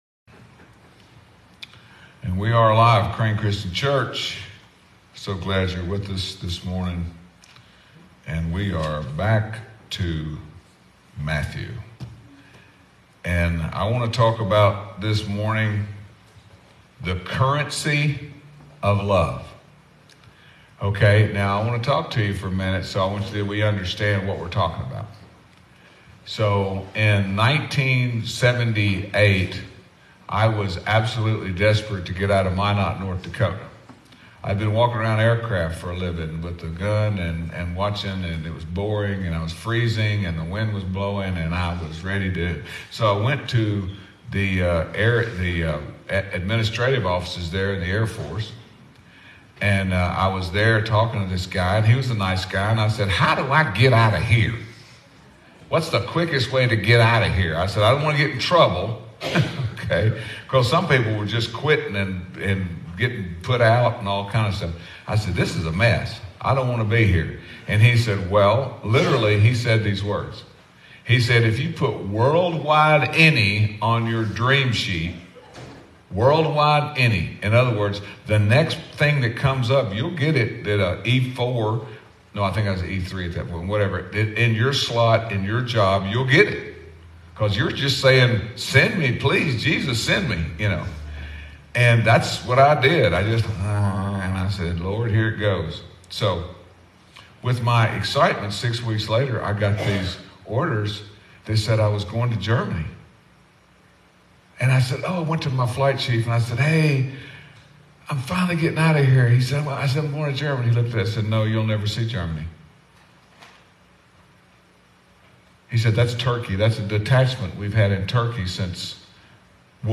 Sunday Sermon 9-17-23